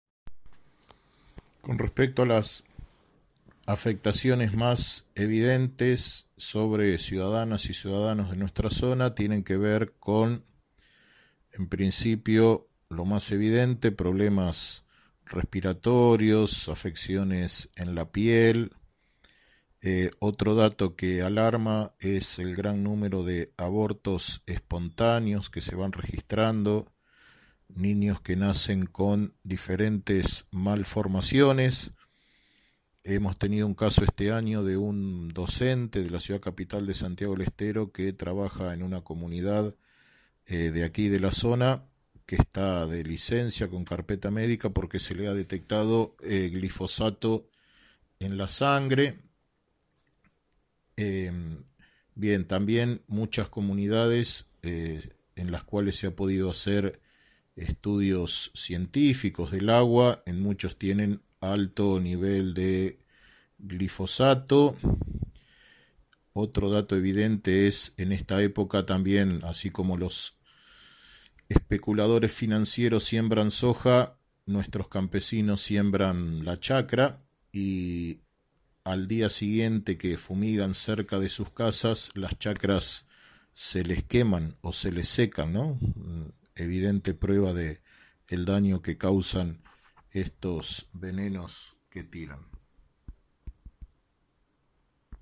Una entrevista realizada por InfoBaires24 se adentra a la realidad que padecen en Santiago del Estero, los pobladores en zonas donde el uso del agrotóxico y las fumigaciones aéreas se cobra vidas humanas y daños forestales de importancia.